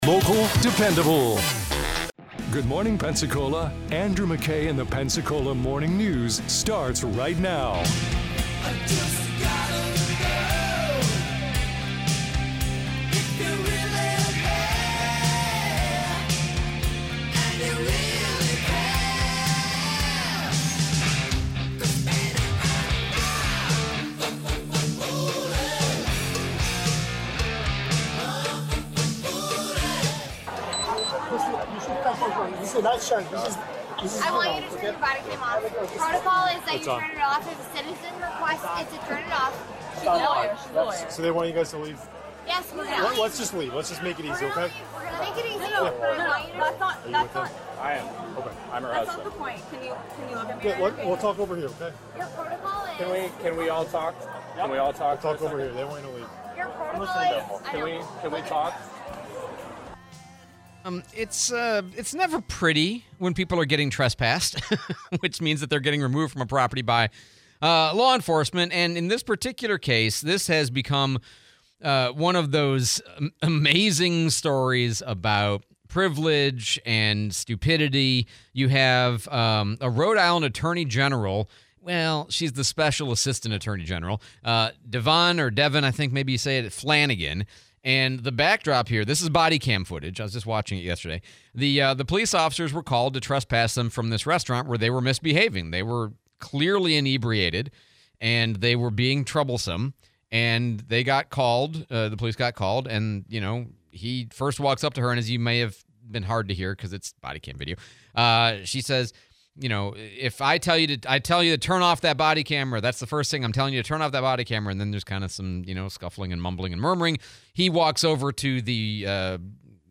Arrest of RI AG, interview with Mayor Reeves